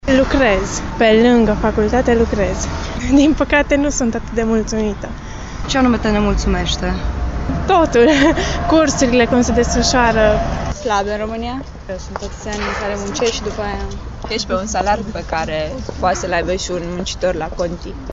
INSERT-Voxuri.mp3